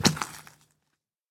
Minecraft / mob / zombie / step1.ogg
step1.ogg